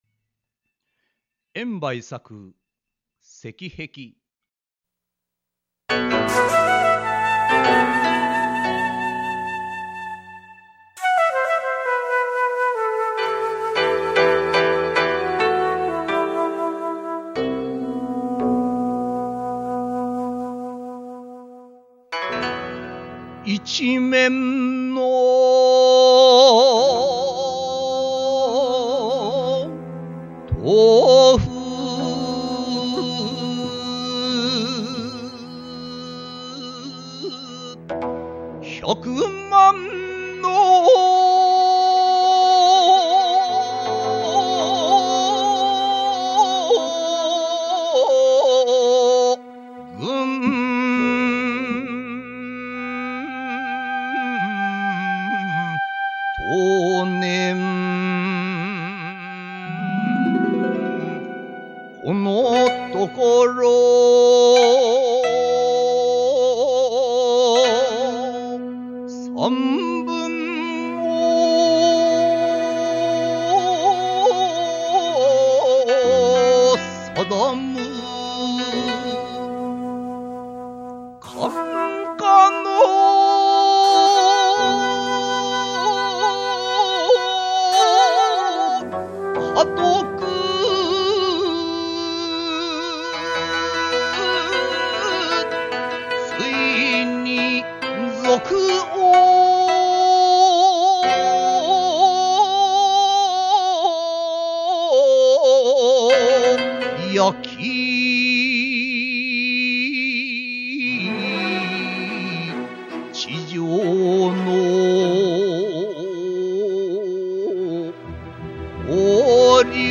漢詩紹介